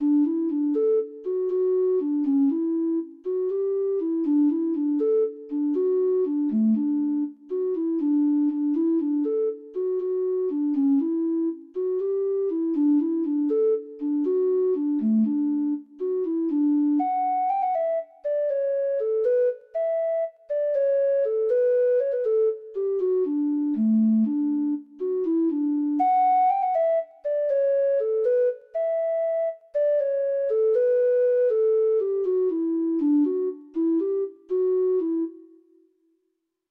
Free Sheet music for Treble Clef Instrument
Irish Slip Jigs